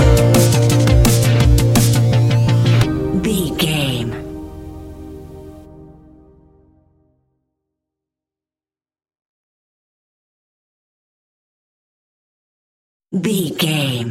Ionian/Major
C♭
electronic
techno
trance
synths
synthwave